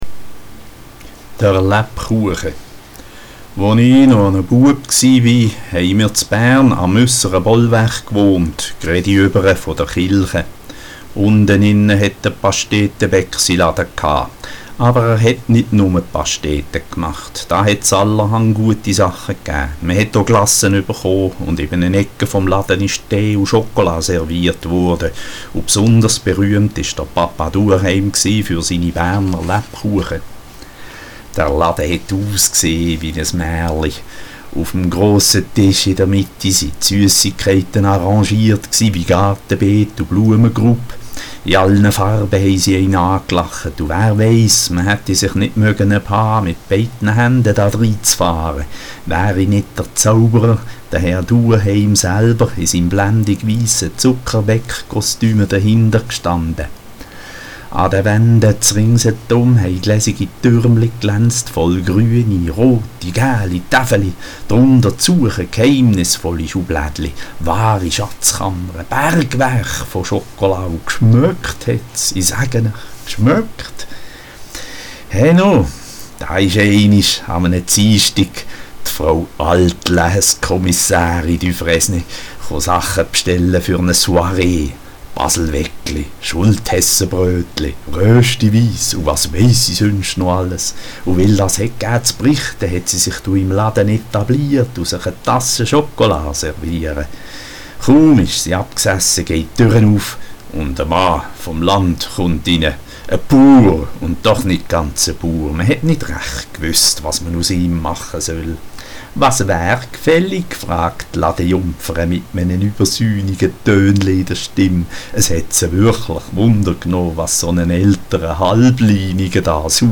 Es chlises bärndütsches Gschichtli vom Rudolf von Tavel. Erzellt vo mir. Hochdeutschsprechende und Berner bitte ich um Entschuldigung. Ich bin weder das eine noch das andere.